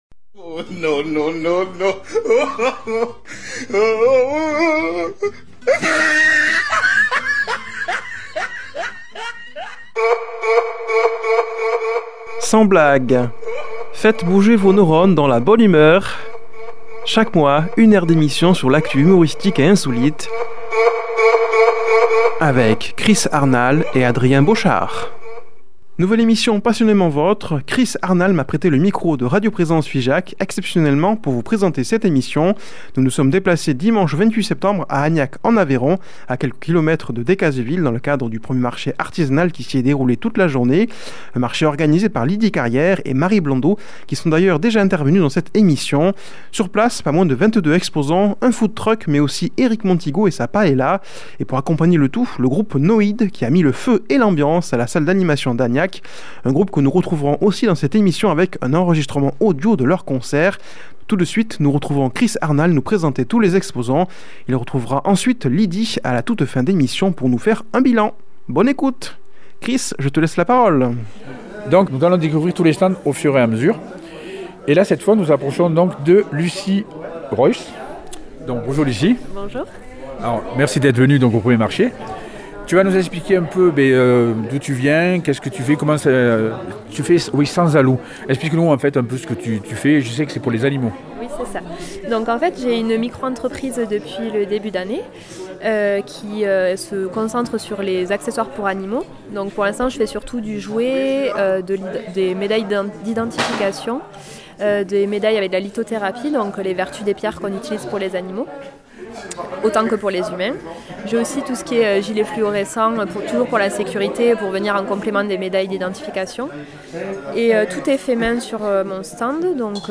Il a pu aussi enregistrer une partie du concert du groupe NOID que nous retrouverons pour la pause musicale.